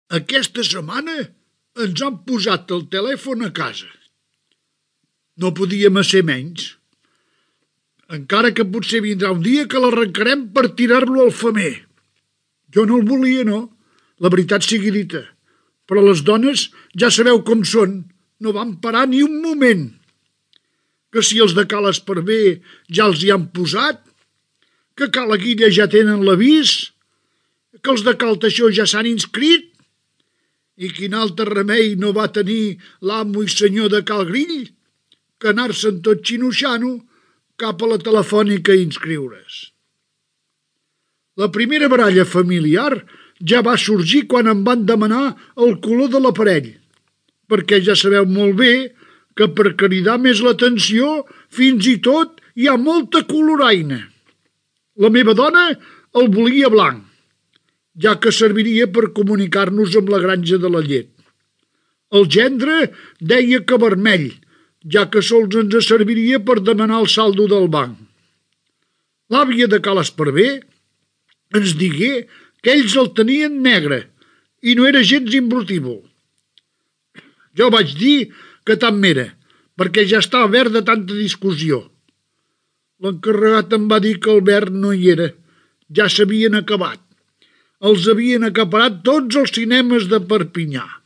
Monòleg "Ja tenim telèfon"
Entreteniment
FM